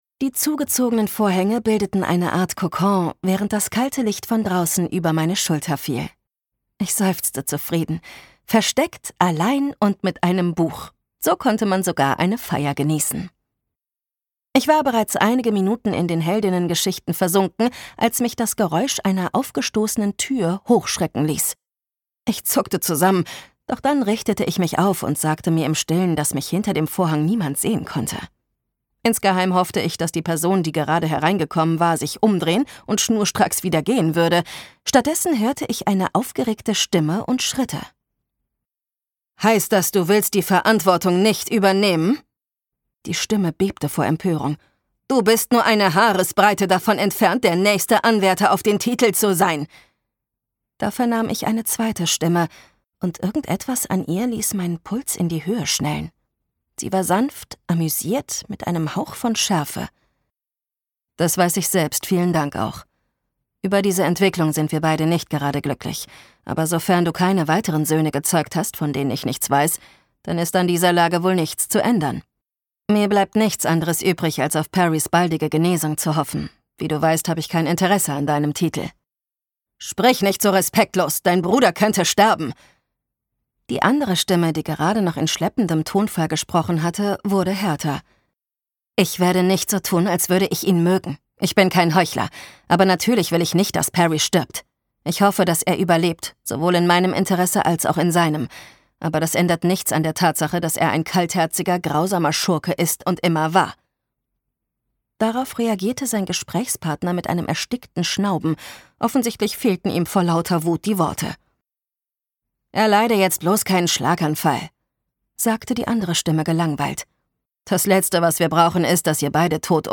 Gekürzt Autorisierte, d.h. von Autor:innen und / oder Verlagen freigegebene, bearbeitete Fassung.
Zur Sprecherin